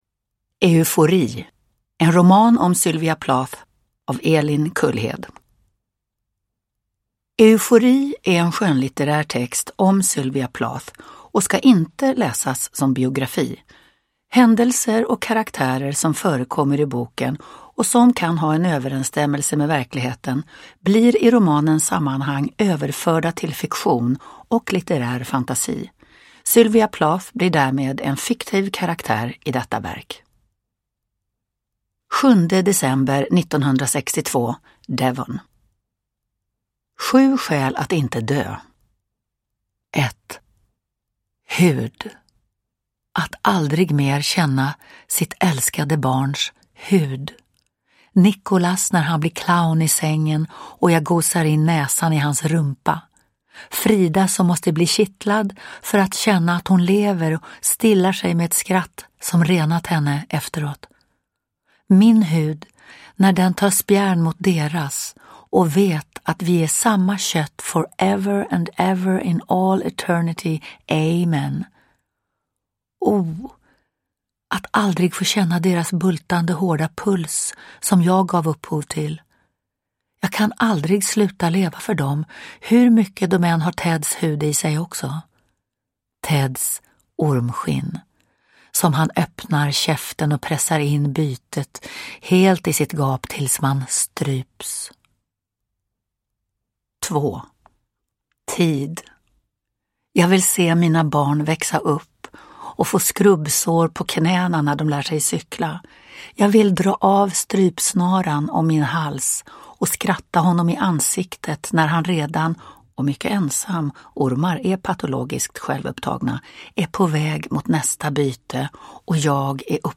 Uppläsare: Lena Endre
Ljudbok